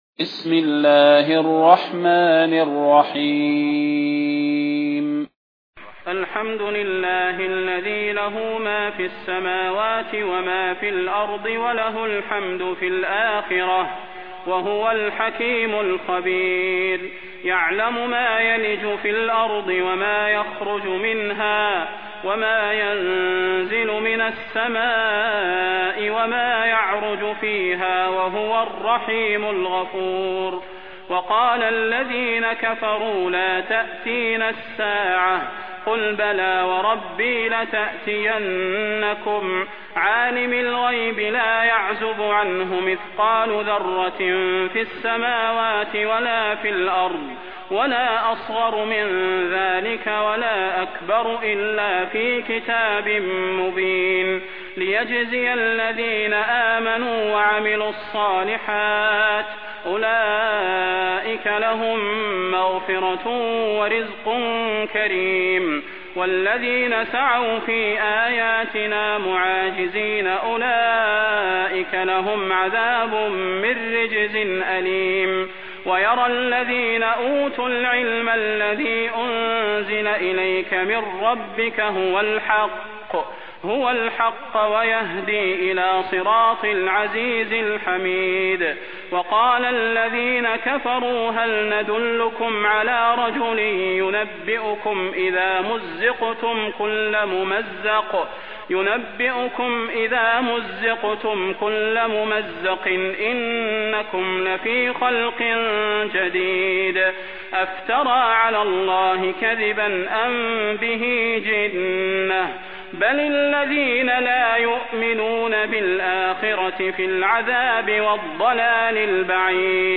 المكان: المسجد النبوي الشيخ: فضيلة الشيخ د. صلاح بن محمد البدير فضيلة الشيخ د. صلاح بن محمد البدير سبأ